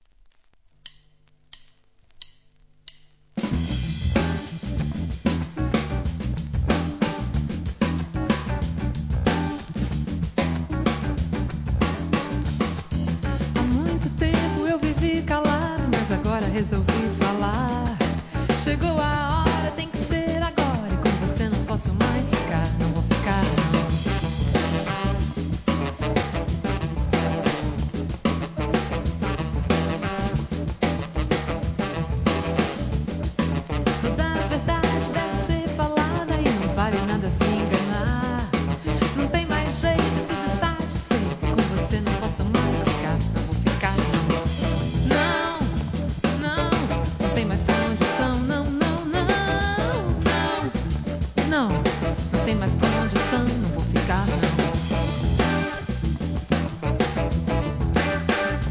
ラテンJAZZ FUNK